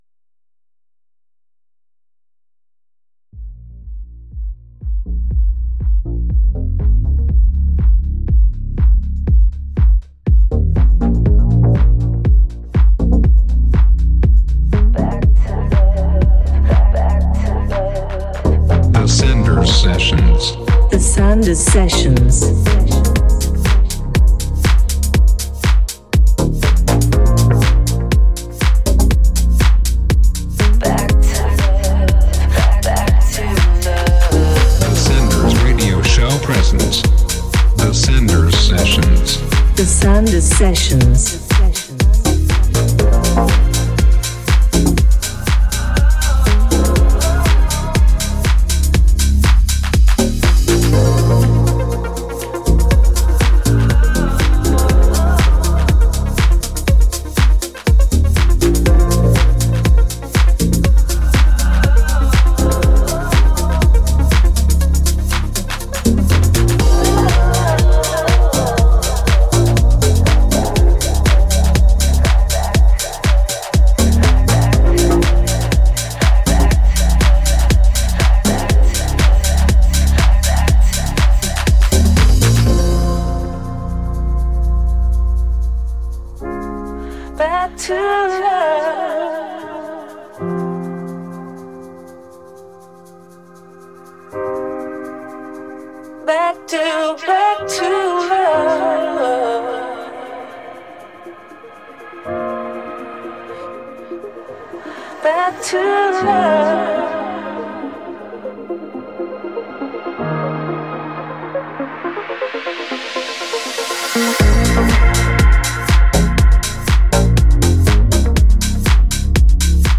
one hour of good sounds mixed